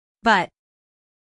but-stop-us-female.mp3